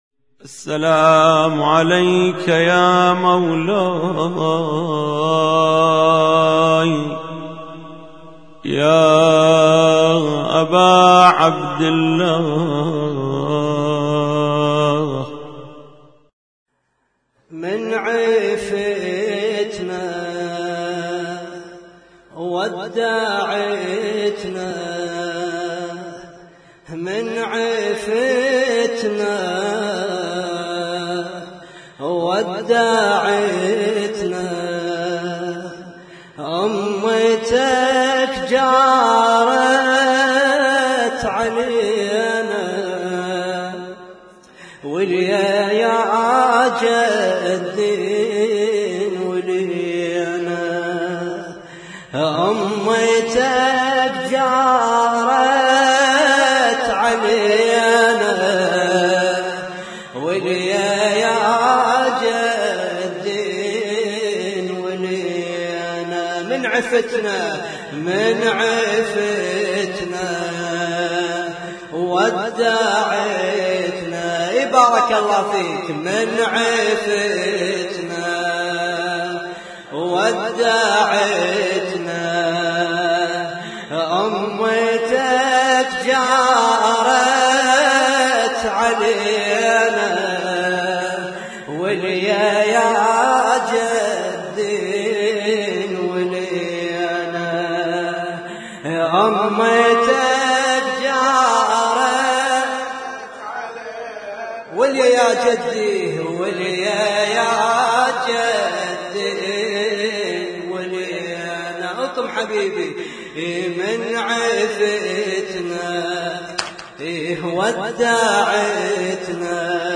Husainyt Alnoor Rumaithiya Kuwait
لطم ليلة 1 محرم 1436